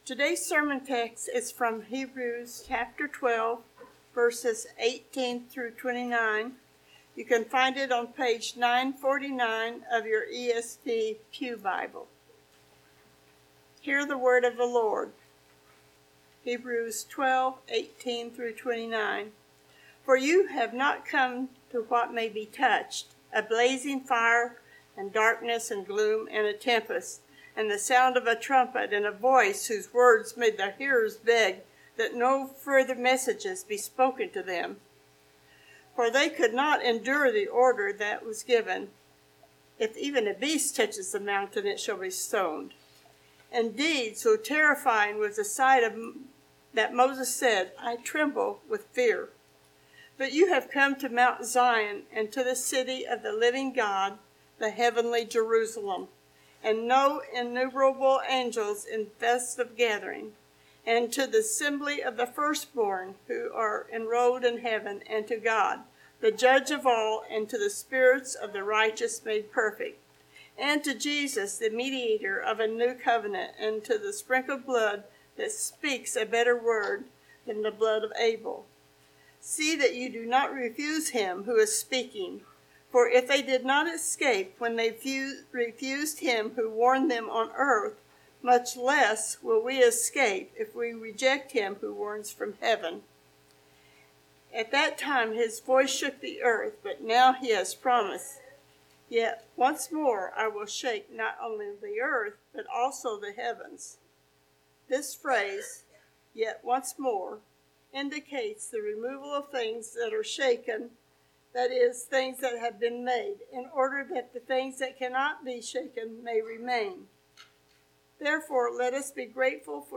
Passage: Hebrews 12:18-29 Service Type: Sunday Morning Related Topics